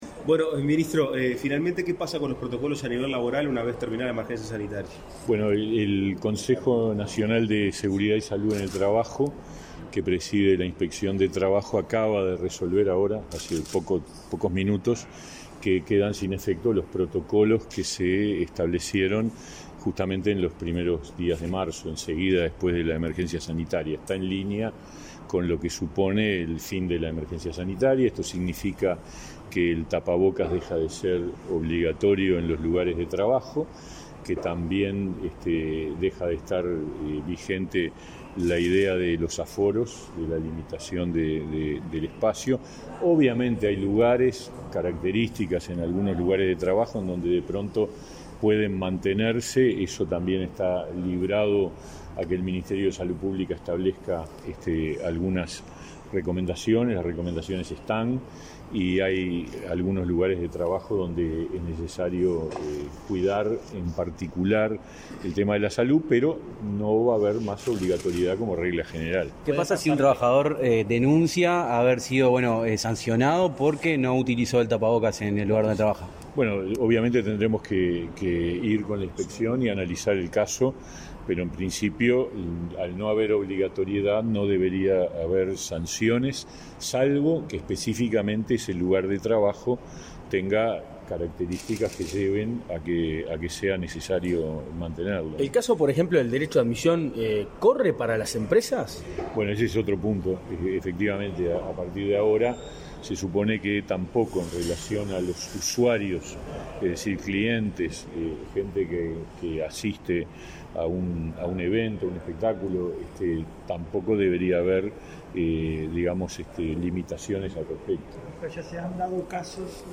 Declaraciones a la prensa del ministro de Trabajo y Seguridad Social, Pablo Mieres